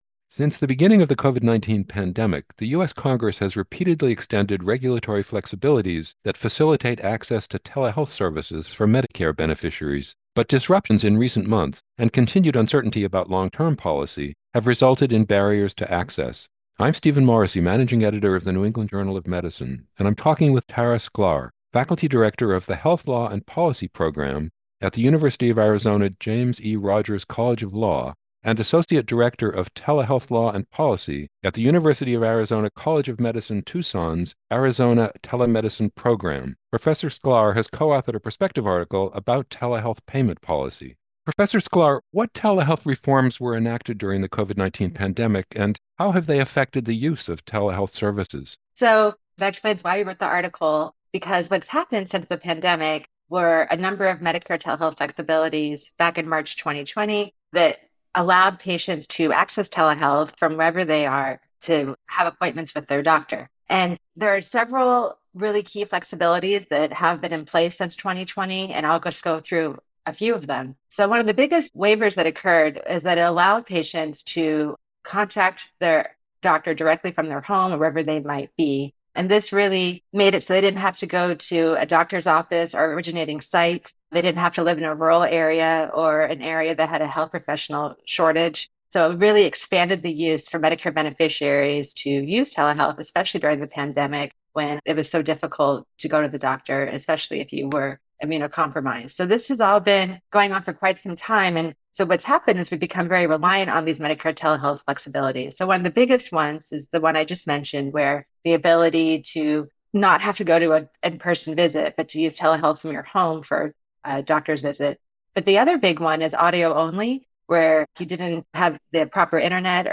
Insightful conversations with leading experts in the field of health care, medical research, policy, and more from the New England Journal of Medicine (NEJM). Each episode examines the many complexities found at the junction of medicine and society.